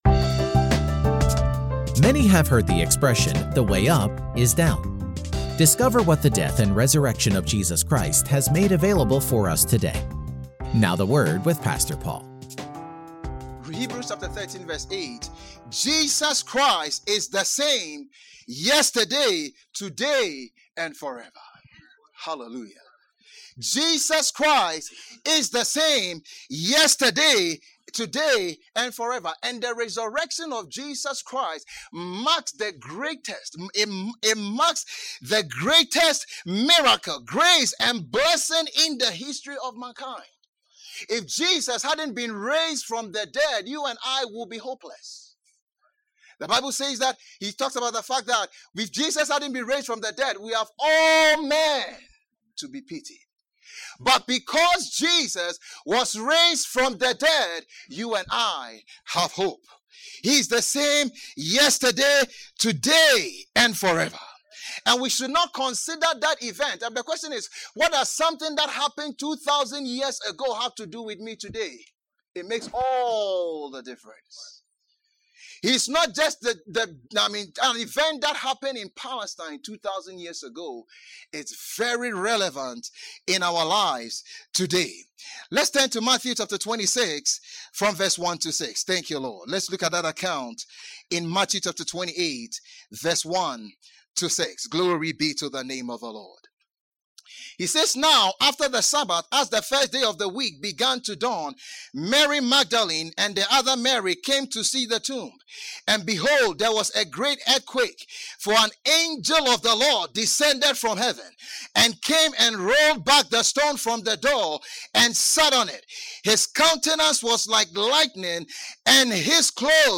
Post category: Sermon